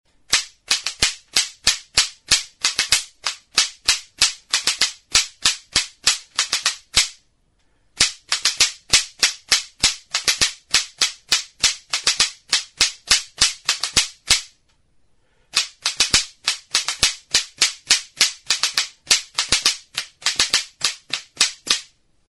Idiophones -> Struck -> Indirectly
Recorded with this music instrument.